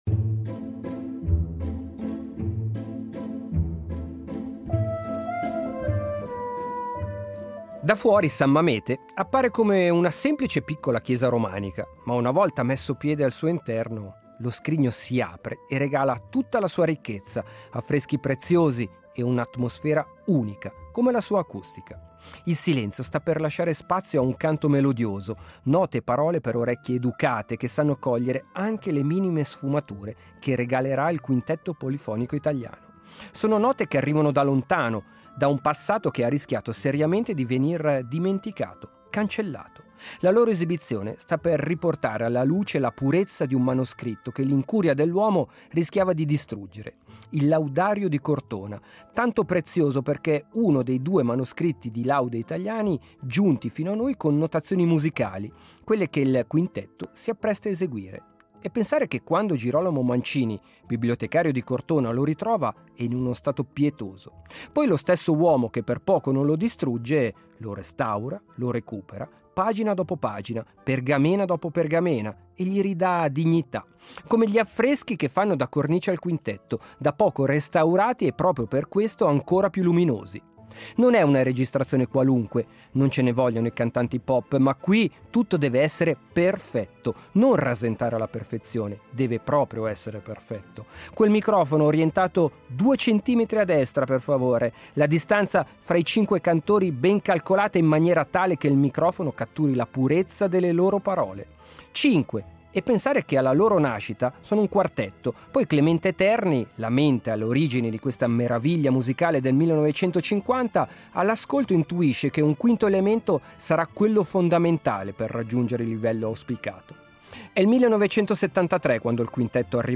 Laudario di Cortona — con il Quintetto Polifonico Italiano. Mezzovico, 1973
Le melodie del Quintetto Polifonico Italiano a San Mamete